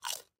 На этой странице вы найдете коллекцию звуков укуса — резких, комичных и ярких, как в мультфильмах.
Надкусил чуть